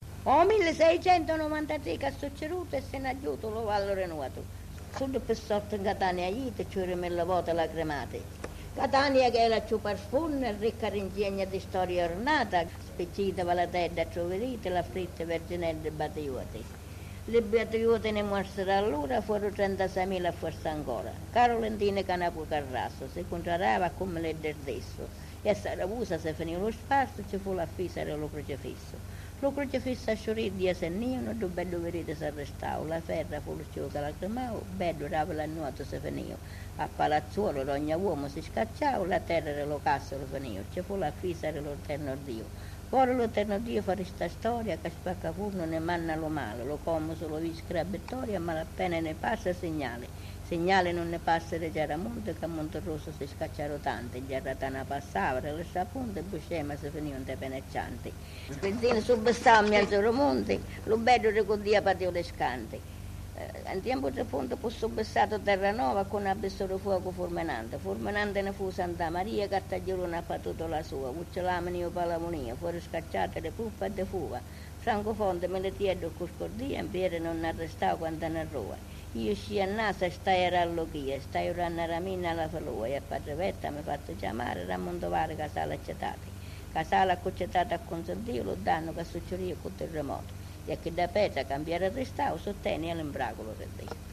Testimonianza
a Chiaramonte Gulfi nel 1979.